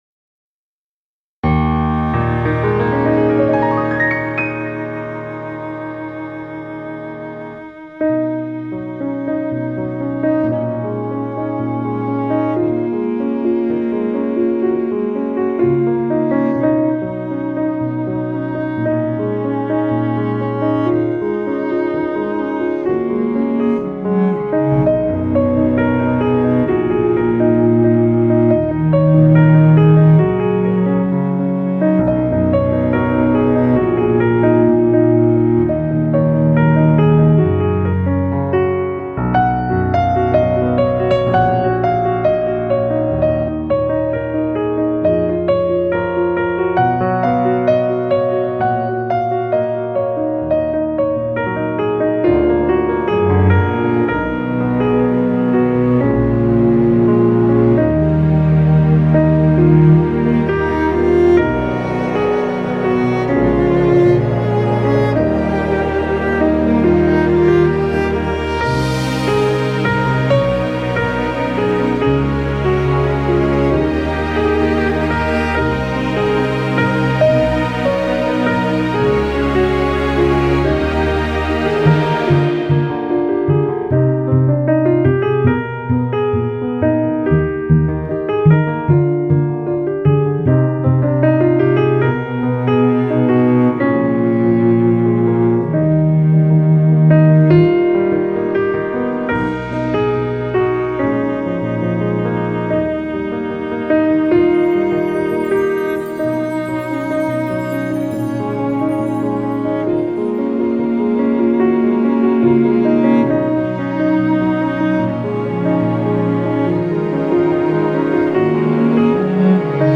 von Piano